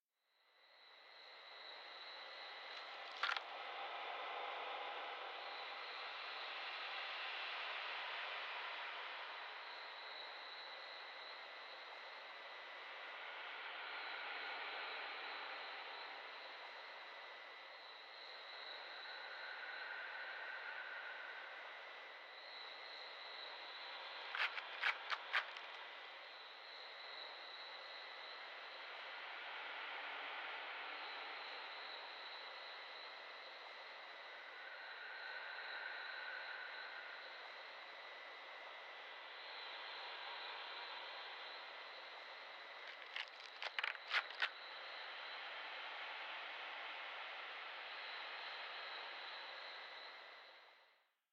03_一楼院子.ogg